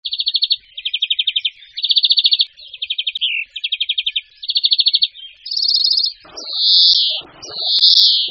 En cliquant ici vous entendrez le chant du Verdier d'Europe